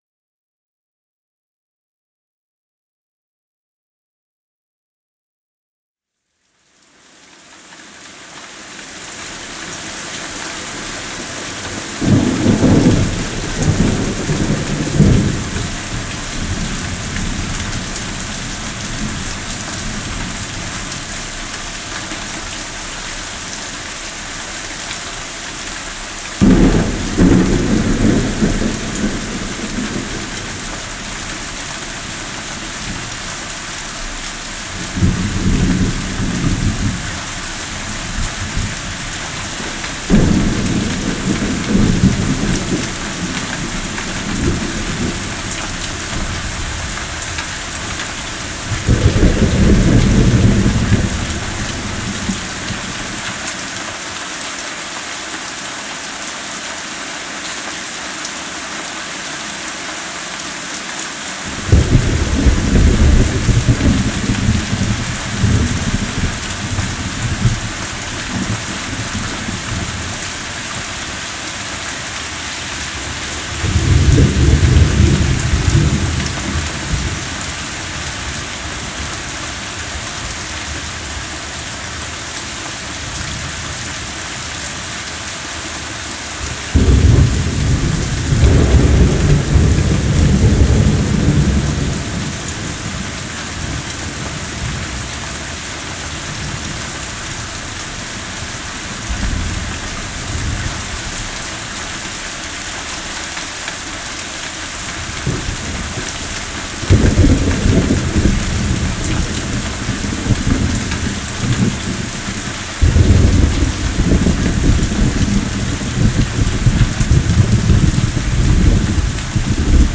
Receding Rain (Sleep Nature Sound)
Receding-Nature-Rain-Dolby-Atmos-HP.mp3